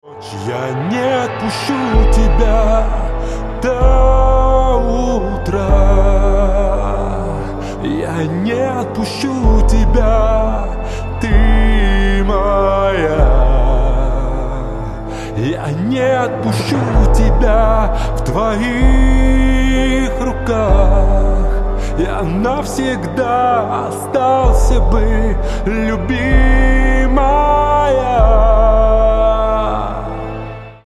• Качество: 128, Stereo
поп
мужской вокал
лирика
спокойные